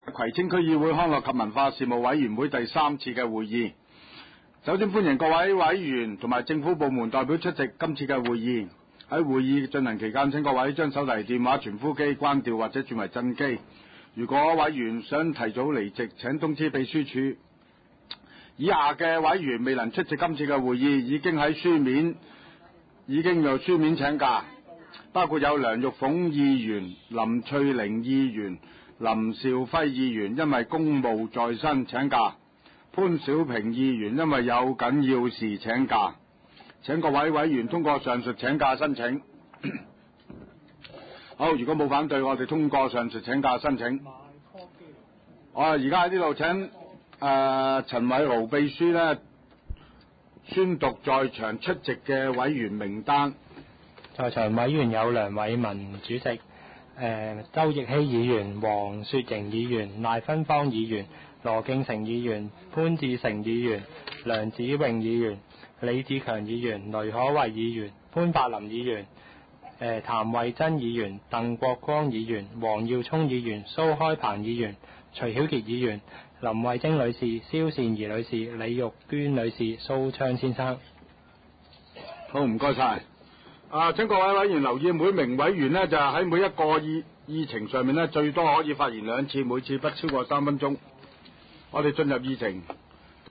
葵青民政事務處會議室
開會詞